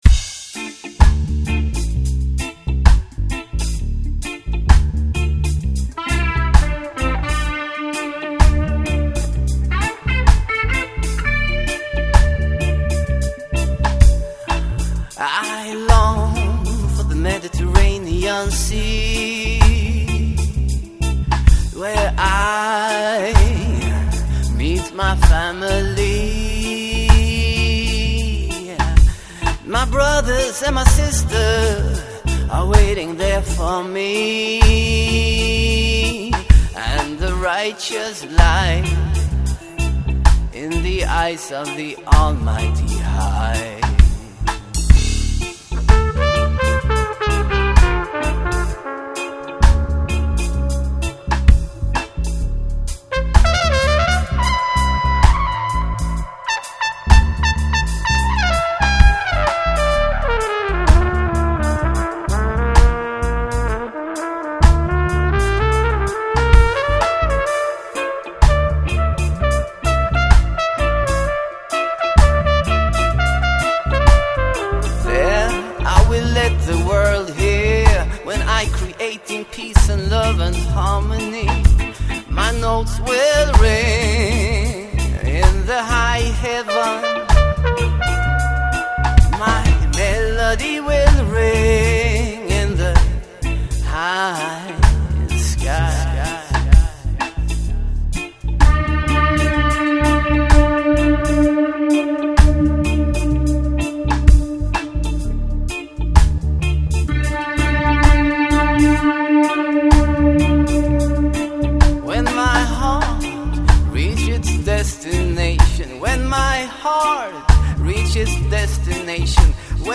Recorded on the north-side OF TOWN